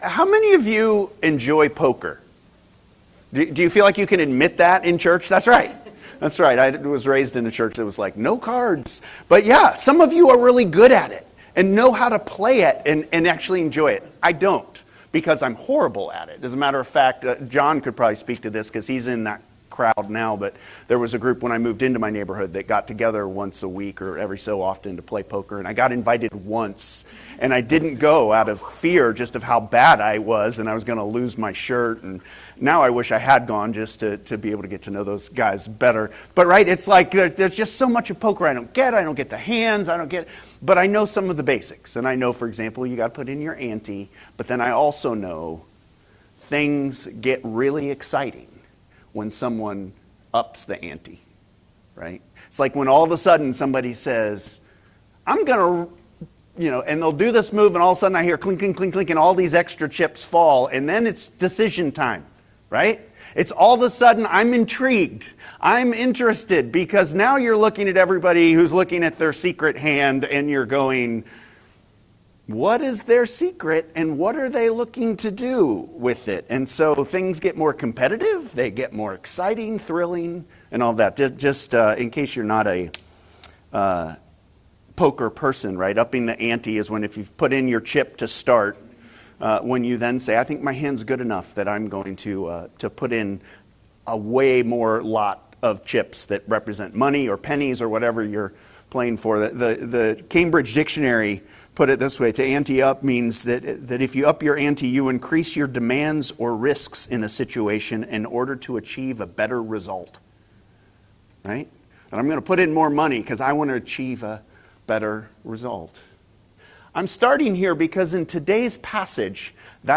Sermons | Hope Presbyterian Church of Crozet